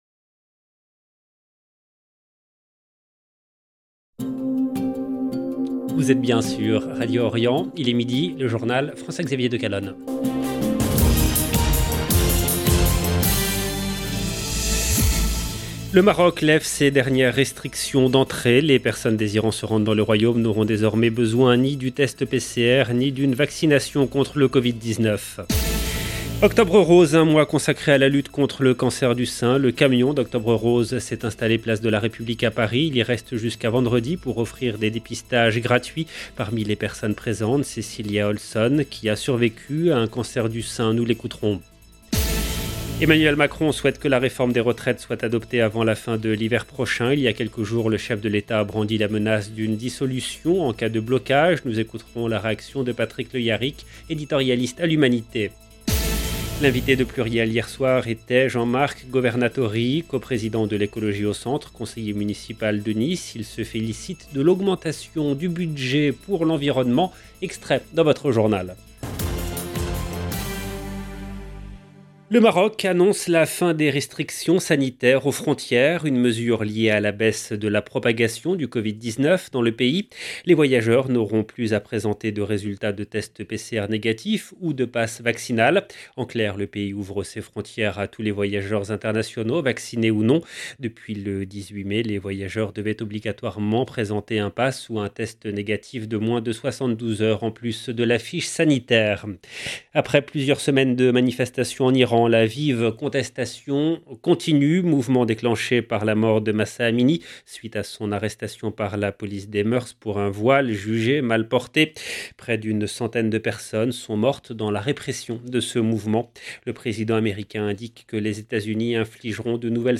Nous écouterons la réaction de Patrick Le Hyaric, éditorialiste à l’Humanité.